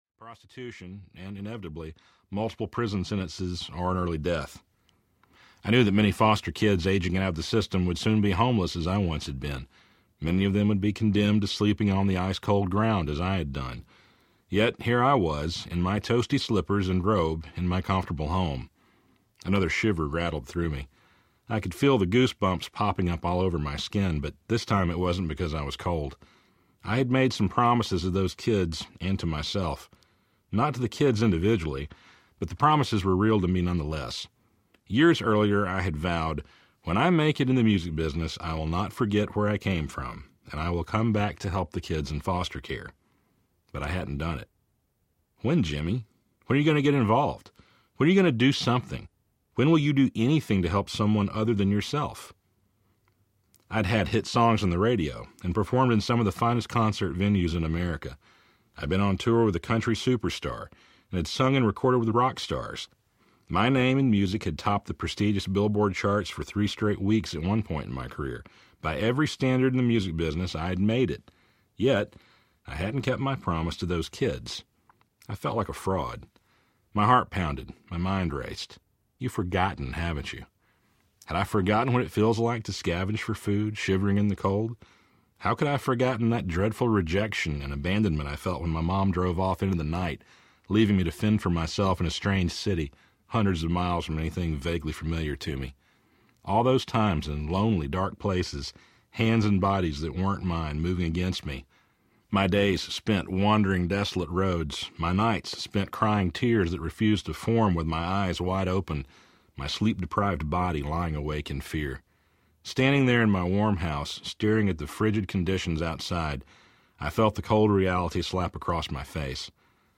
Walk to Beautiful Audiobook